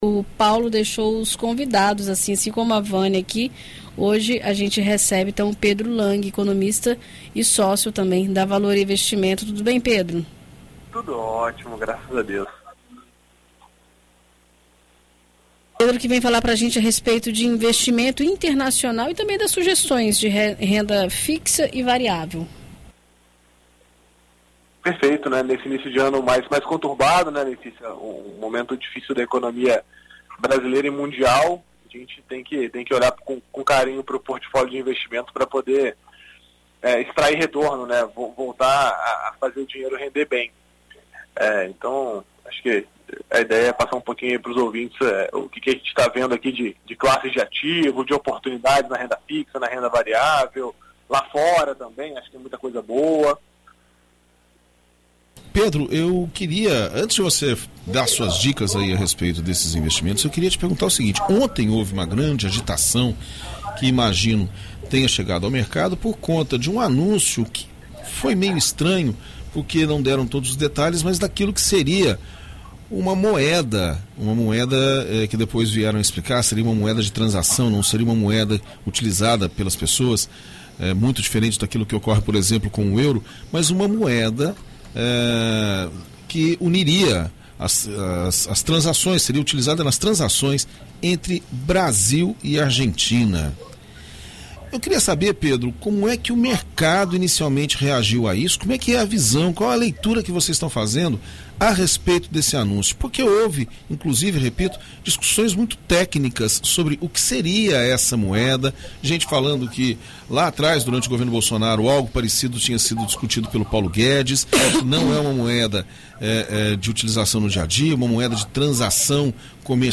Em entrevista à BandNews FM ES nesta terça-feira (24)